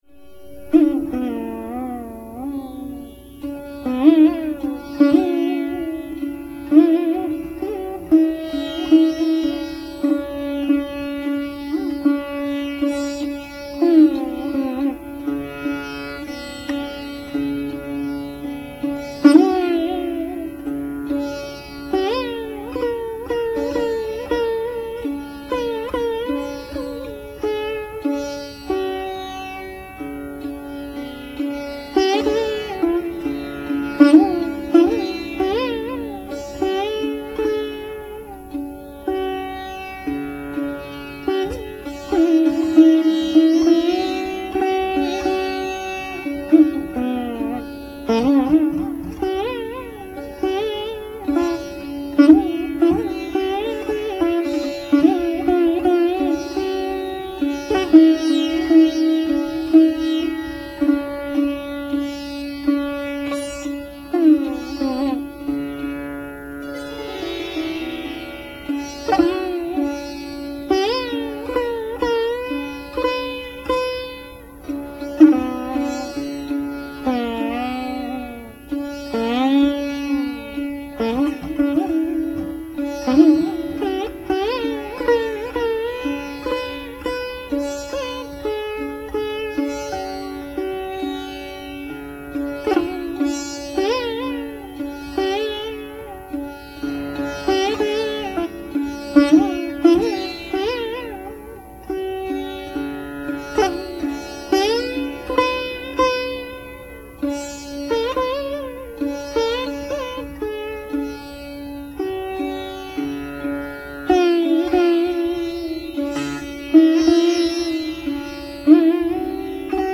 A few instrumental selections follow.